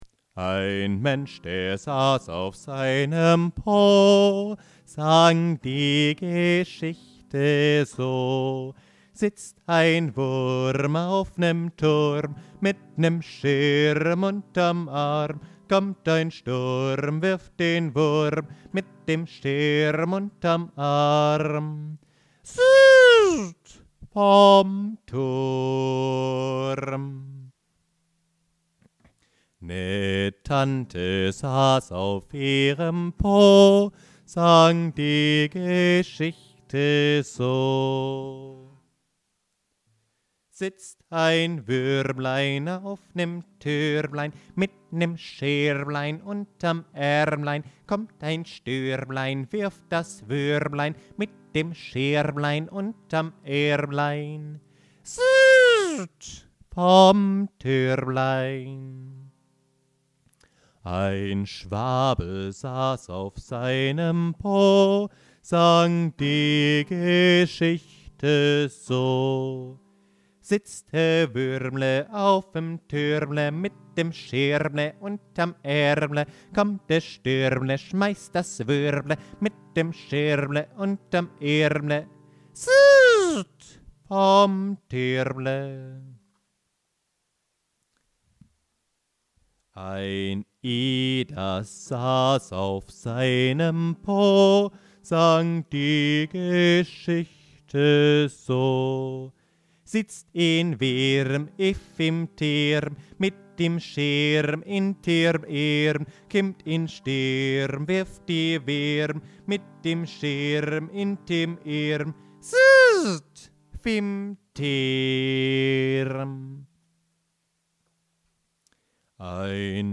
C G7, C G7, C G7, C